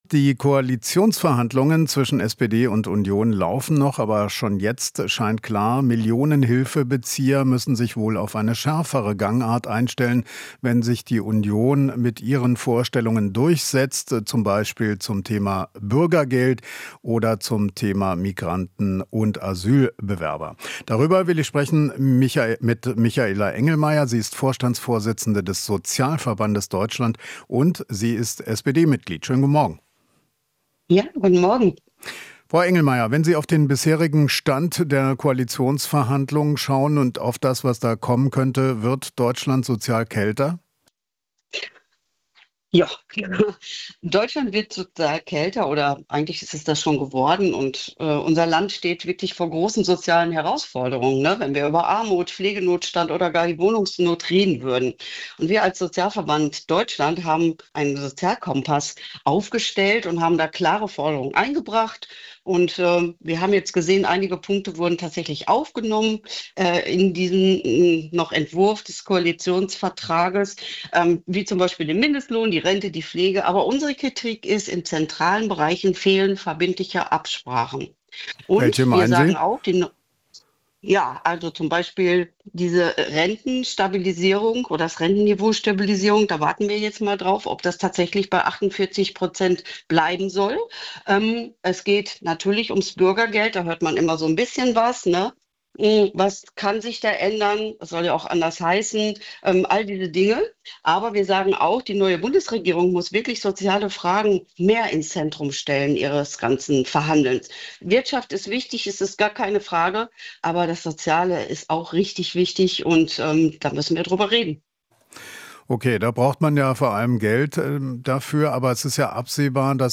Interview - Sozialverband: Soziale Fragen müssen mehr im Zentrum stehen